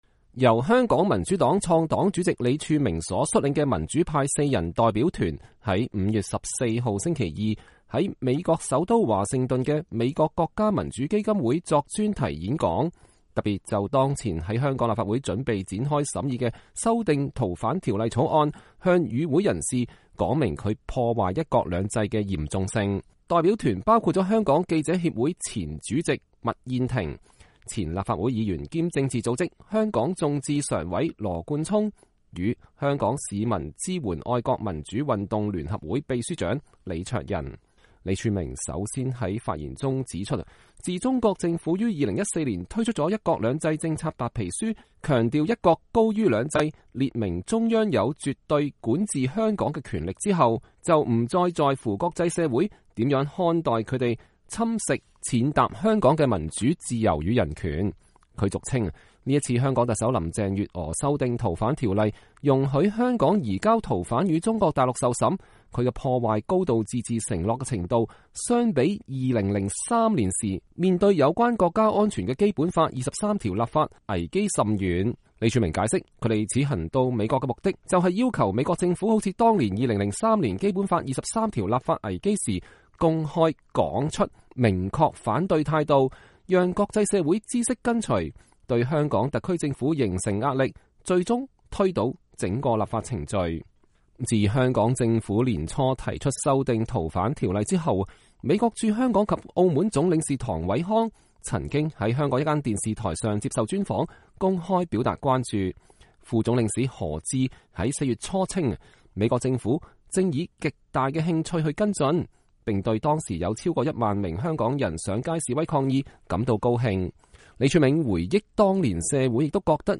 李柱銘(左一)所率領的代表團在美國國家民主基金會作專題演講。